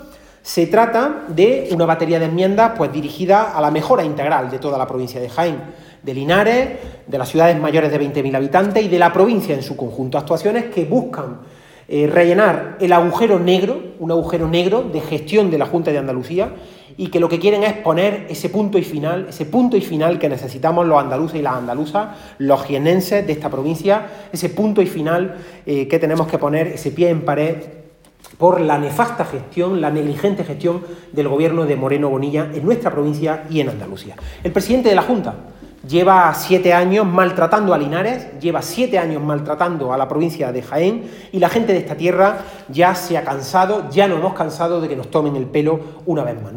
En rueda de prensa en Linares, tras una reunión del Grupo Parlamentario Socialista,
Cortes de sonido